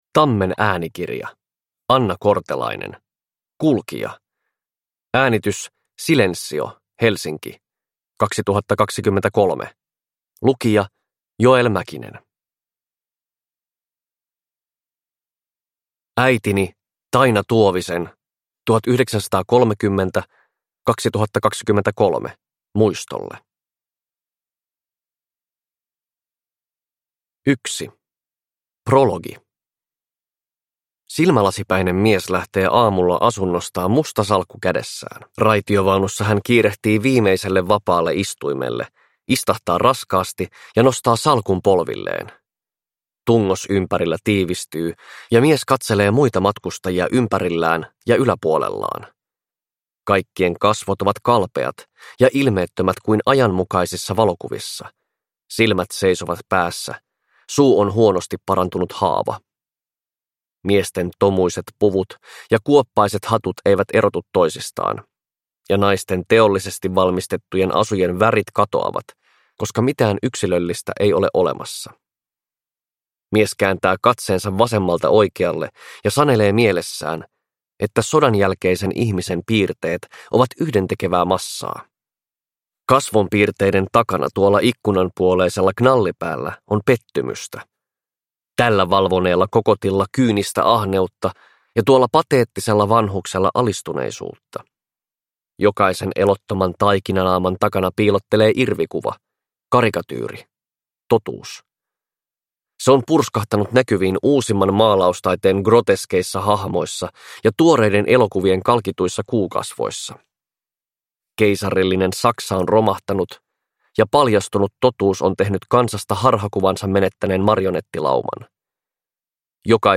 Kulkija – Ljudbok – Laddas ner